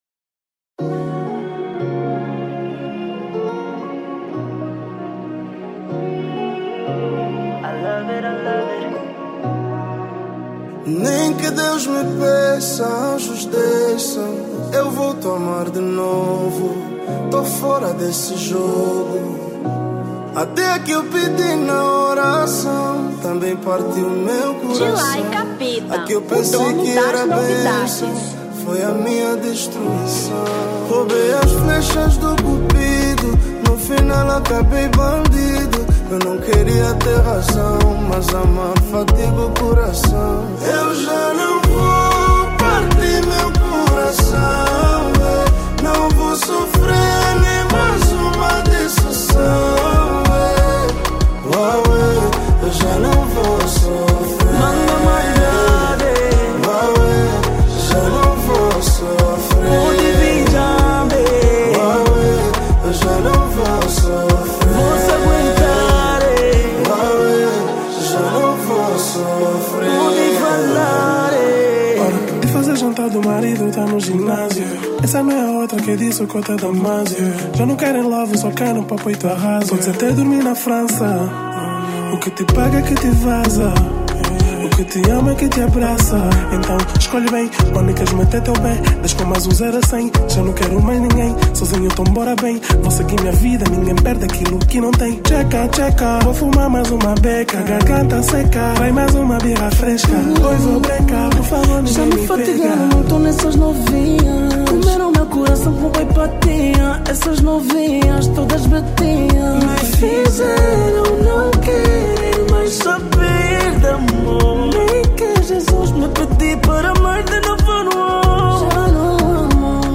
Dance Hall 2025